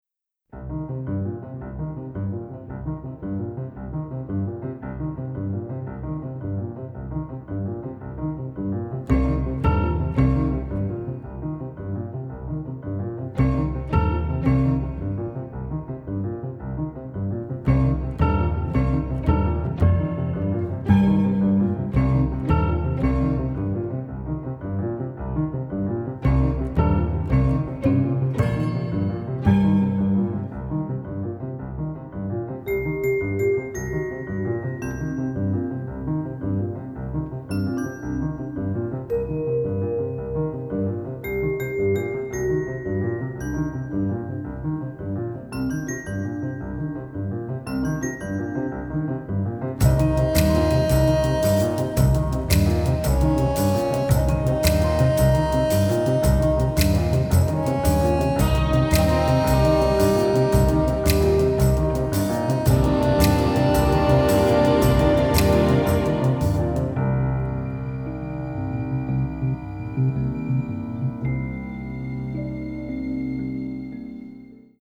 picaresque western